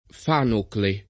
[ f # nokle ]